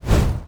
etfx_shoot_fireball2.wav